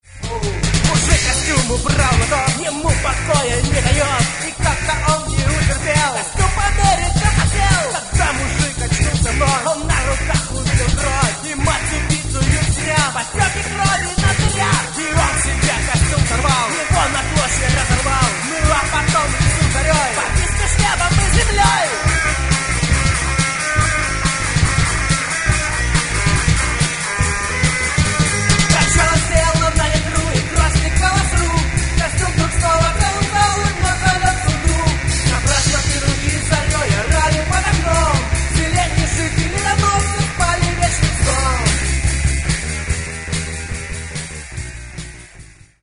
Гитары, бас, вокал
Барабаны
фрагмент (269 k) - mono, 48 kbps, 44 kHz